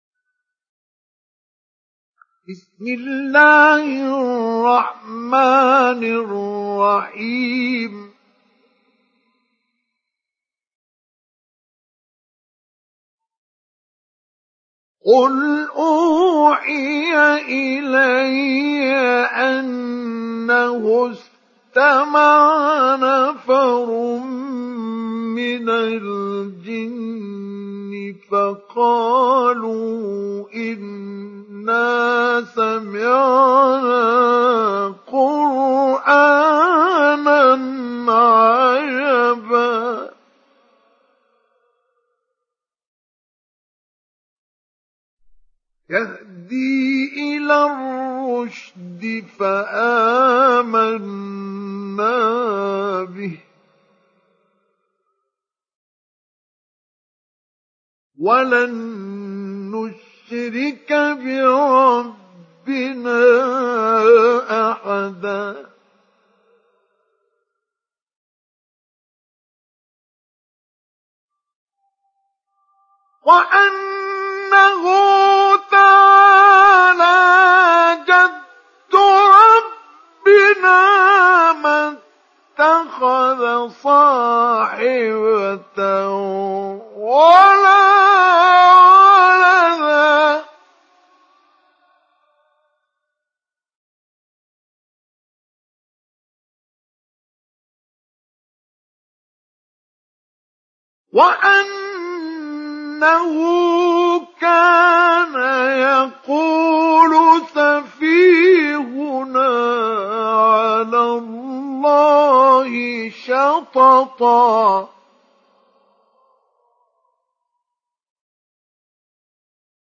Télécharger Sourate Al Jinn Mustafa Ismail Mujawwad